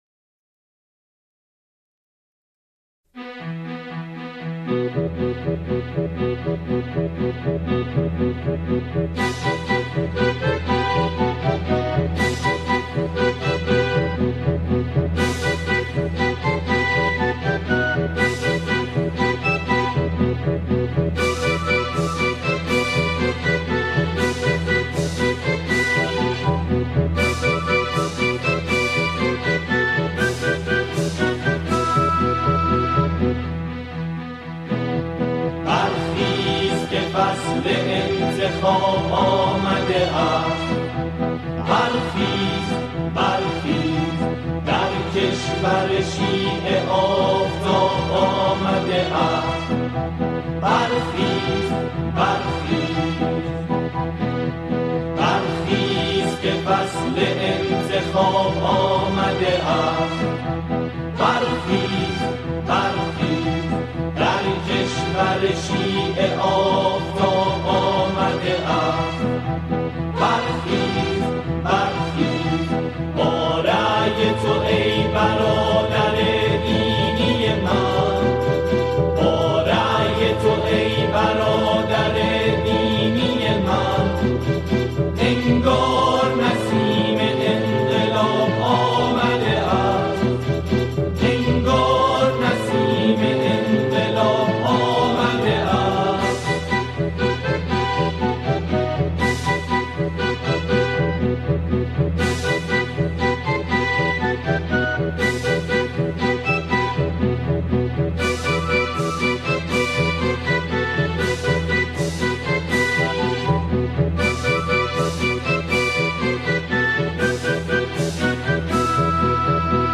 با صدای اعضای گروه کر ساخته شده